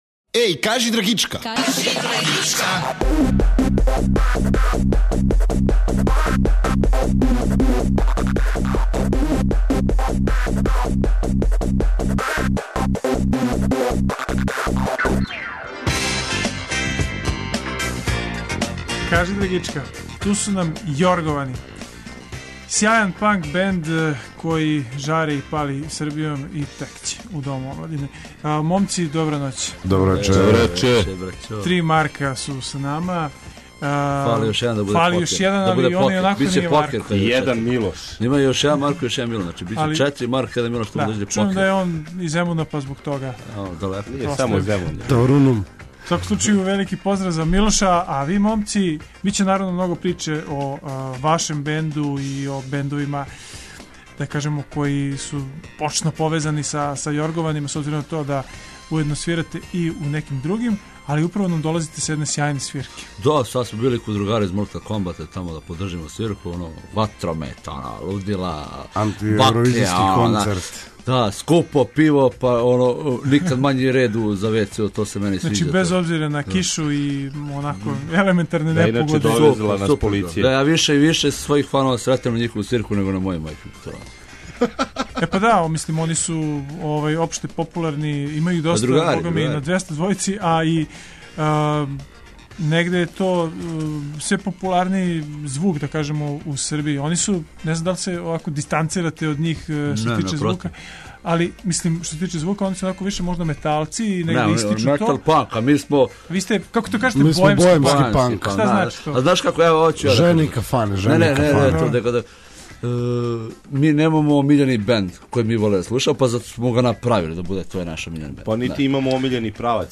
Гости вечерашње емисије унеће мало буке у ваше звучнике. У питању су момци који су се недавно окупили и свој бенд назвали ’’Јорговани’’ а музички жанр: боемски панк.
Покушаћемо, уз бучну свирку, да разјаснимо да ли и како ангажовани панк може бити универзално решење свих нагомиланих проблема модерног друштва као што су беспарица, стресови, незапосленост и диктатура.